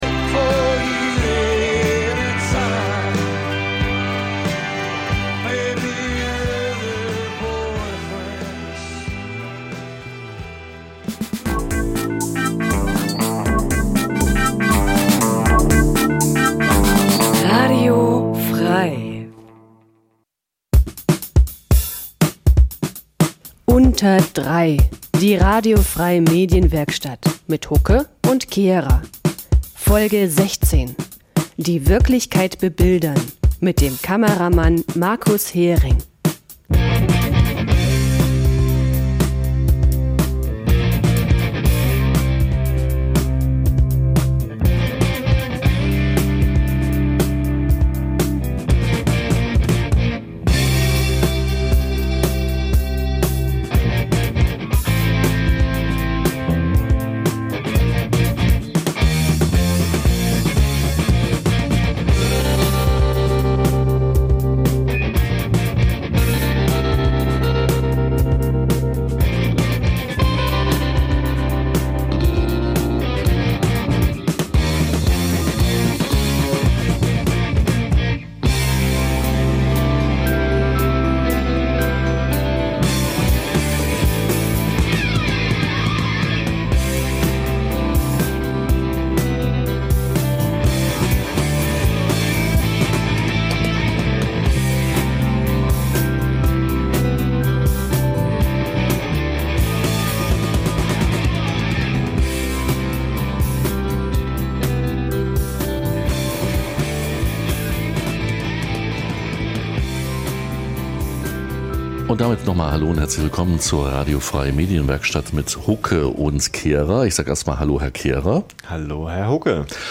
In �Unter Drei� widmen wir uns einmal im Monat dem Th�ringer Journalismus. In die einst�ndigen, thematischen Sendungen laden wir stets einen Gast ein, der oder die aus seinem/ihrem journalistischen Alltag im Freistaat erz�hlt. Dabei wollen wir nicht nur die Unterschiede der drei Gewerke Radio, Fernsehen, Zeitung/Online beleuchten, sondern auch einen Blick auf verschiedene Sender und Verlage werfen.